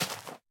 sounds / dig / grass2.ogg
grass2.ogg